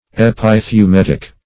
Epithumetic \Ep`i*thu*met"ic\
epithumetic.mp3